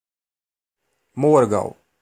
Ääntäminen
France (Paris): IPA: [dø.mæ̃]